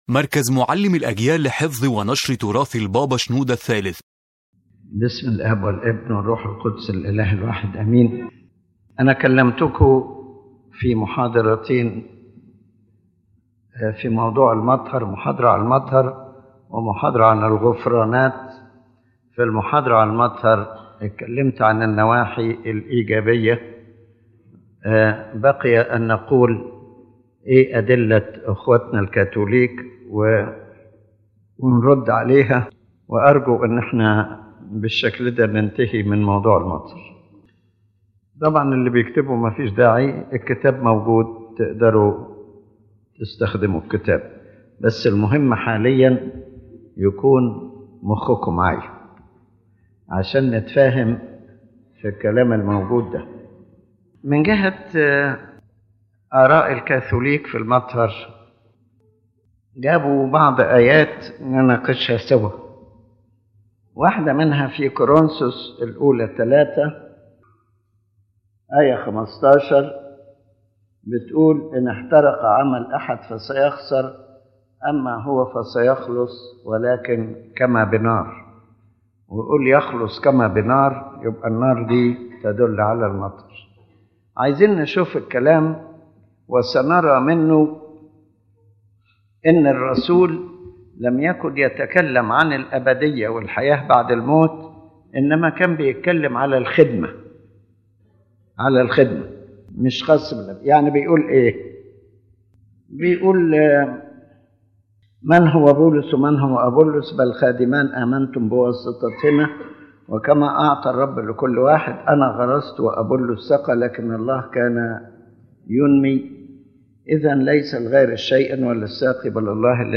يتناول قداسة البابا شنوده الثالث في هذه المحاضرة الرد على الأدلة الكتابية التي يستند إليها الإخوة الكاثوليك في إثبات عقيدة المطهر، موضحًا التفسير الأرثوذكسي الصحيح للنصوص الكتابية، ومؤكدًا أن هذه الآيات لا تشير إلى وجود مطهر، بل تتحدث عن أمور أخرى مثل اختبار الأعمال، أو الدينونة الأبدية، أو شمول خضوع الخليقة لله.